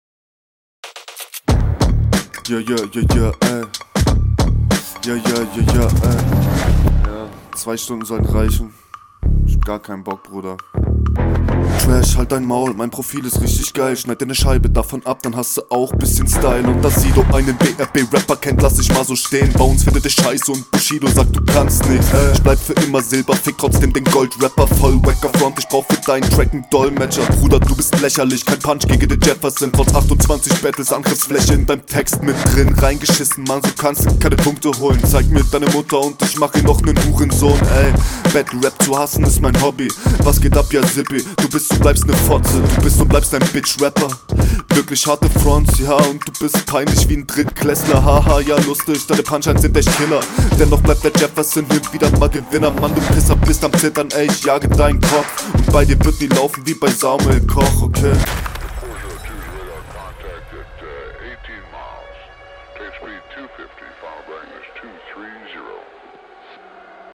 Textlich zwischen irrelevant und ganz gut aber dafür flowlich und vom Mixing her super.
Der Beat ist ultrageil und du kommst sehr strong darauf.